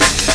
amen3.wav